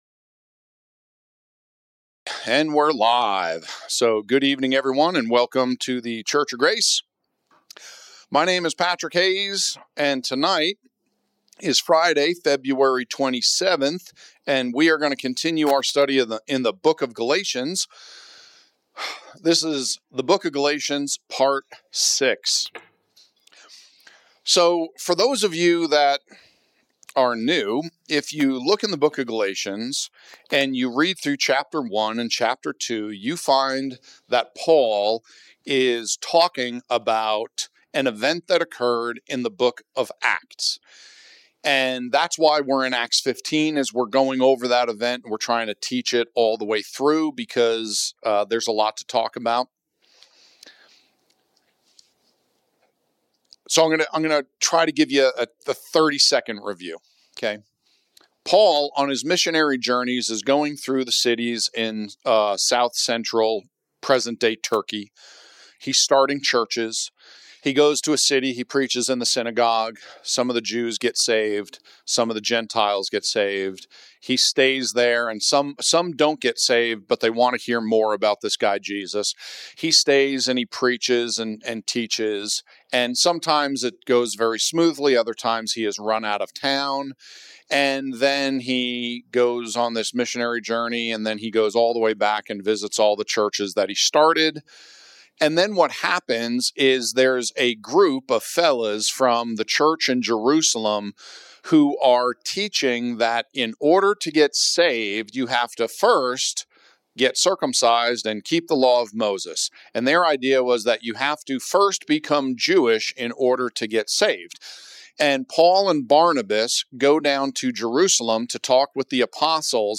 These podcasts are almost always done live.